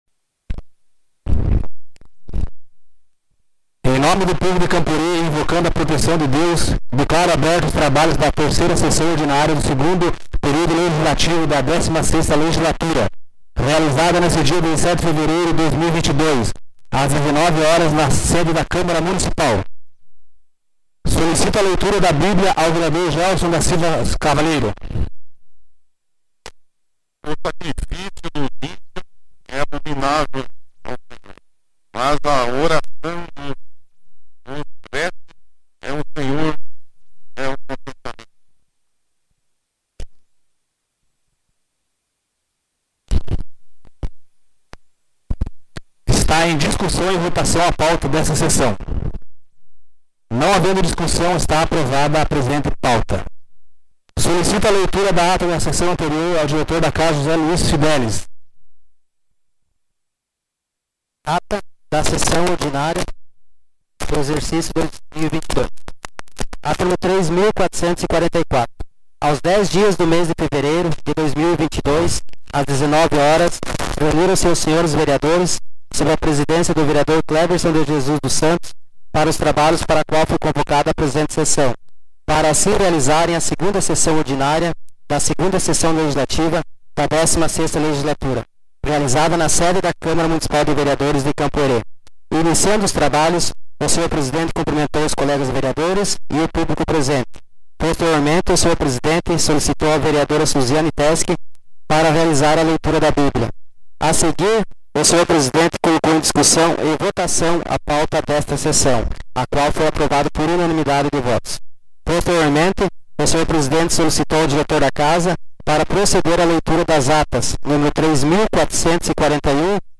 Sessão Ordinária 17 de fevereiro de 2022